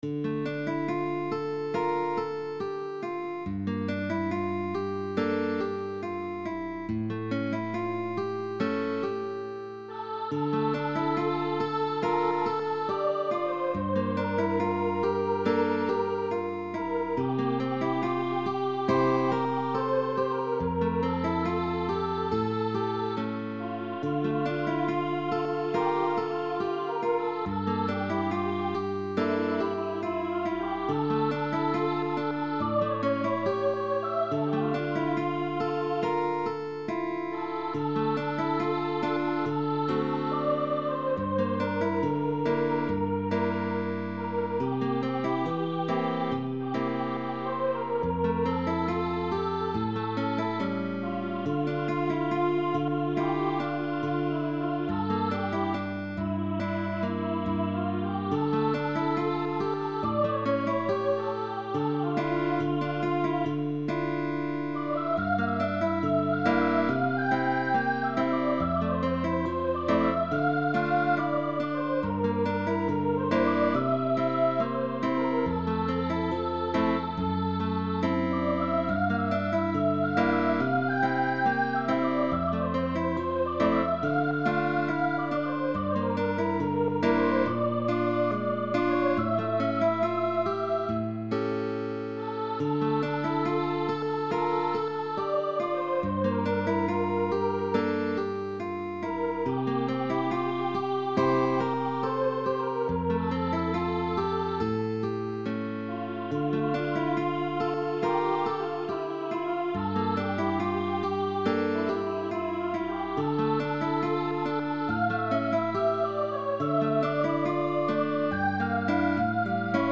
SOPRANO & GUITAR Bolero (Mexican melodic song).